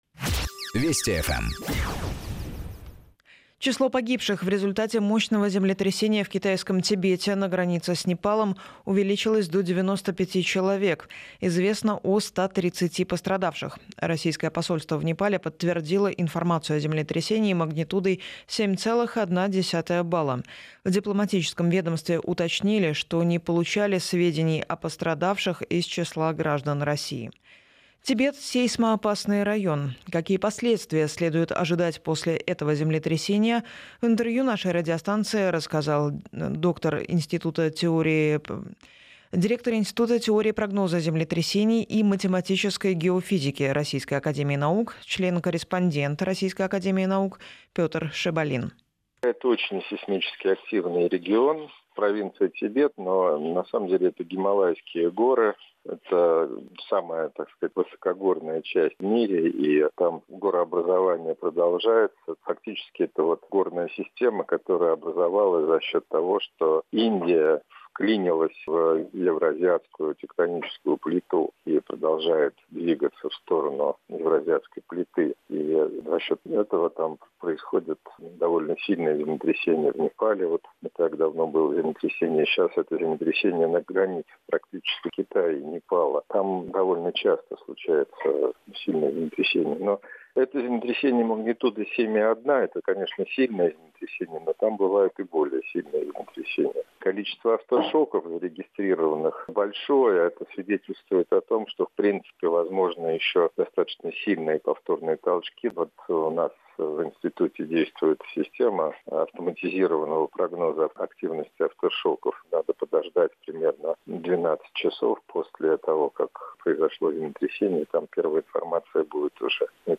Ознакомиться с отрывком эфира можно ниже: